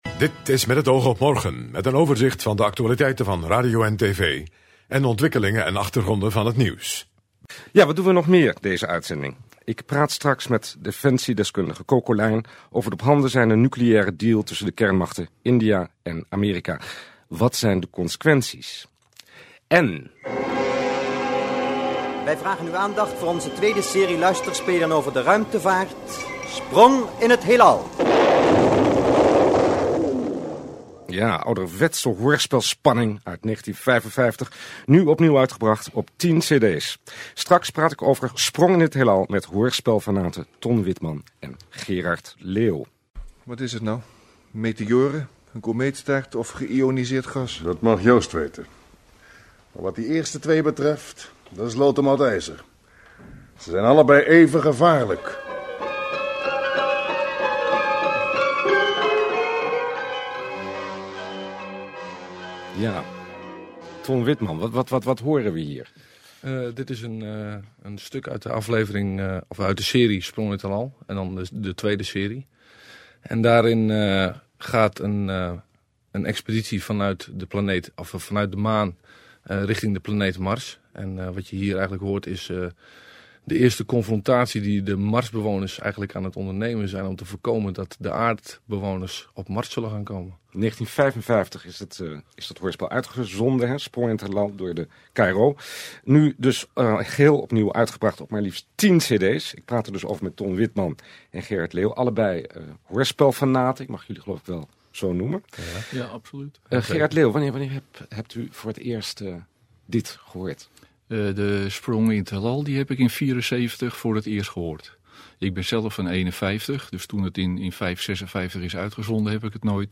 Interview in “Met het oog op morgen”